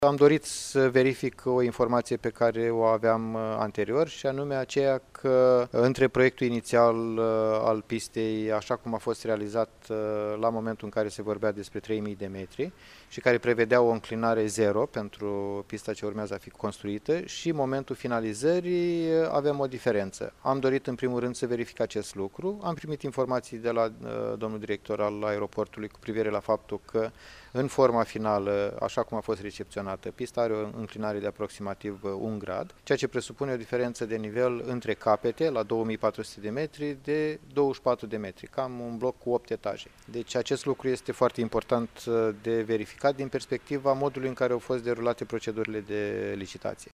Prefectul Iaşului a precizat că doreşte să verifice procedurile de licitaţie: